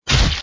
hurt2.mp3